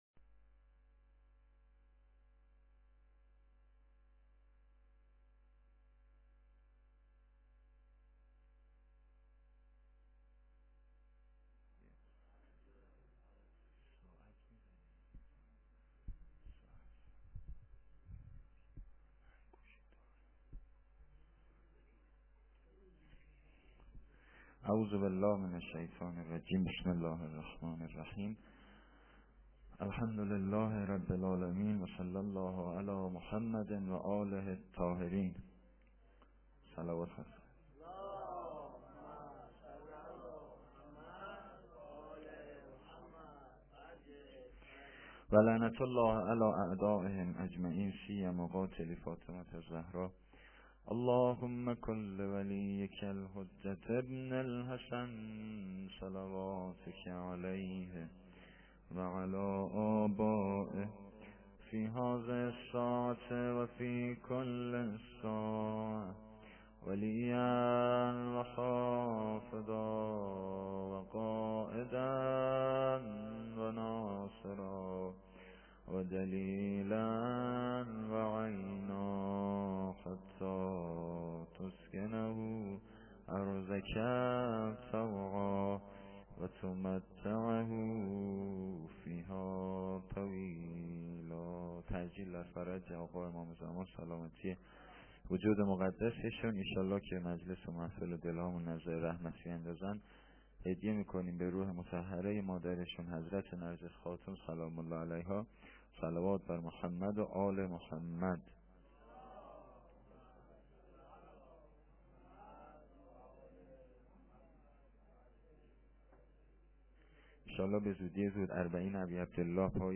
sokhanrani-7.lite.mp3